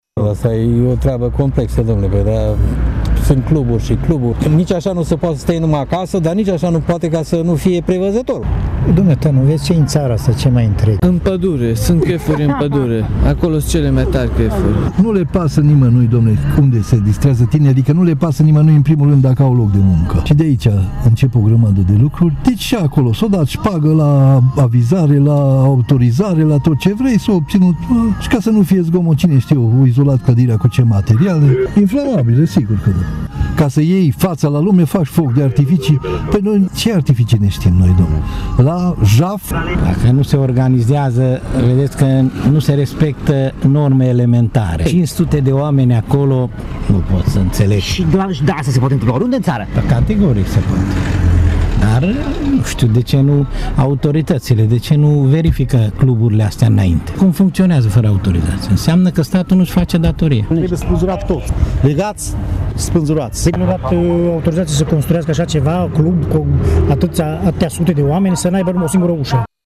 Târgumureșenii sunt revoltați de tragedia de aseară. Lipsa autorizațiilor necesare ,dar și corupția pot conduce oricând la evenimente similare, spun oamenii intervievați de reporterul Radio Tîrgu-Mureș